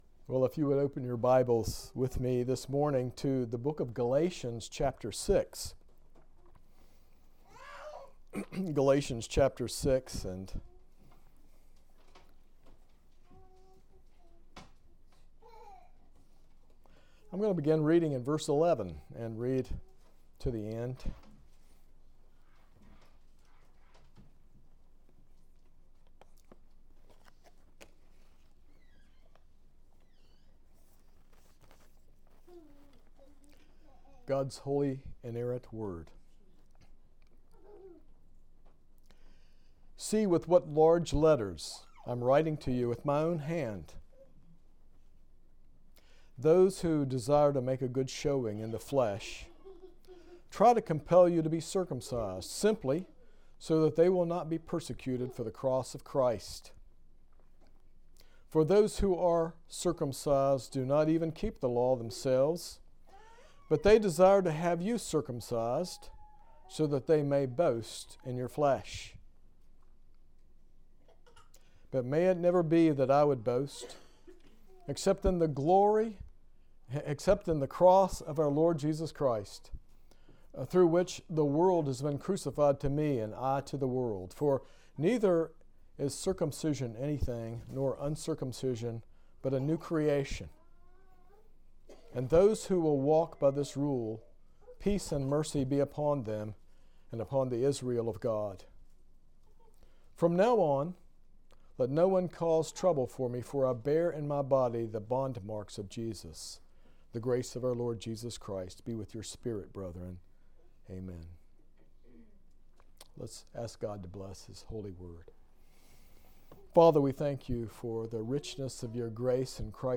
This sermon explores Galatians 6:14, emphasizing why believers should only boast in the cross of Jesus Christ. It delves into the nature of God’s glory, justice, mercy, and love as revealed in the darkest yet most beautiful moment in history.